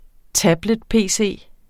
Udtale [ ˈtablεd- ]